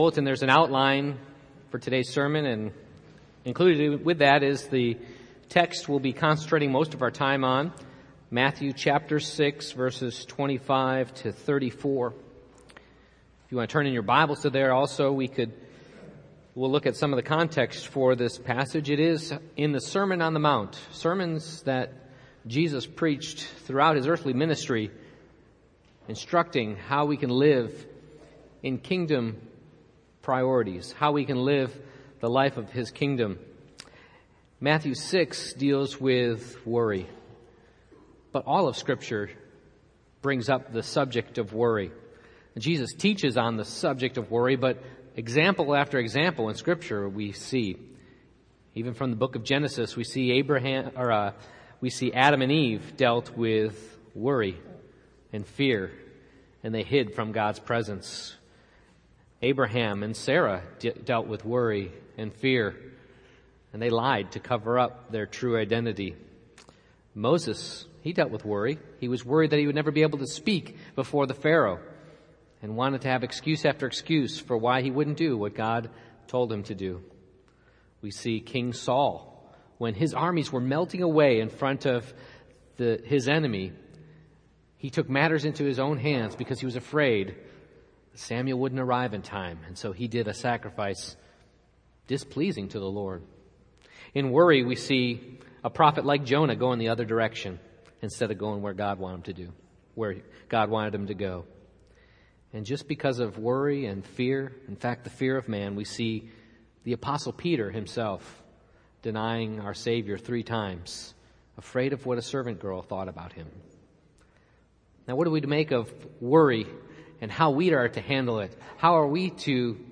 Matthew 6:25-34 Service Type: Morning Worship I. The Occasion- Who